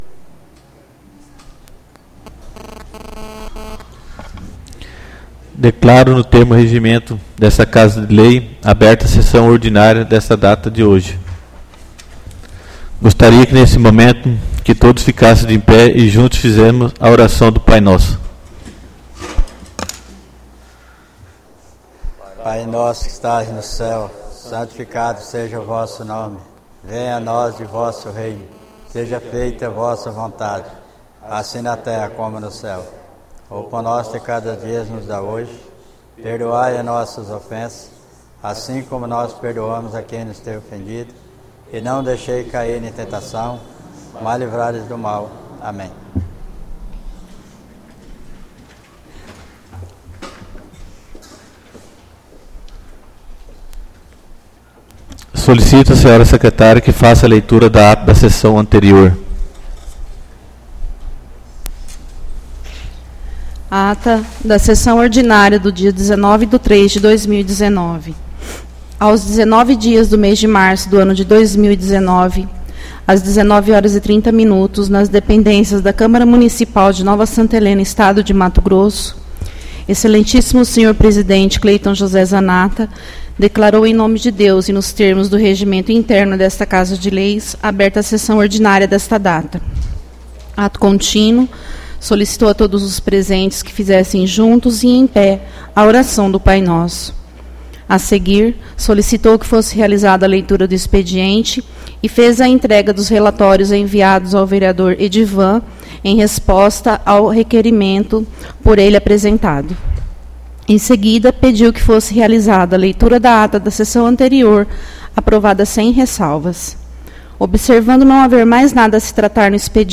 Audio da Sessão Ordinária do dia 26/03/2019